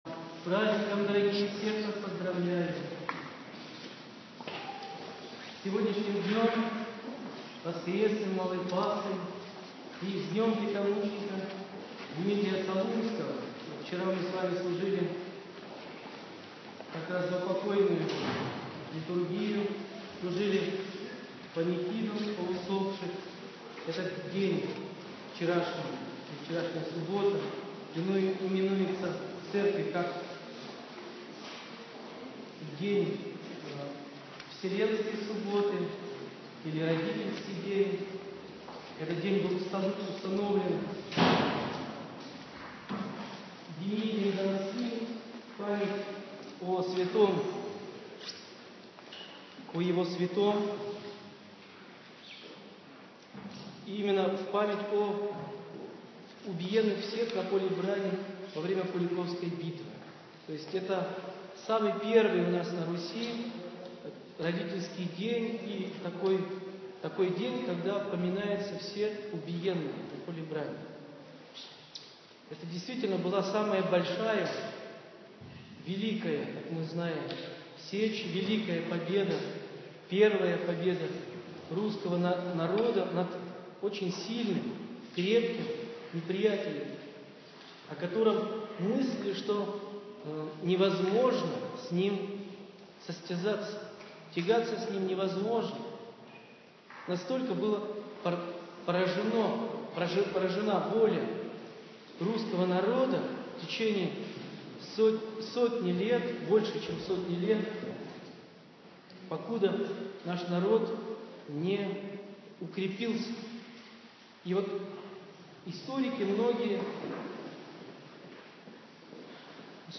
Проповедь после Божественной Литургии 8.11.15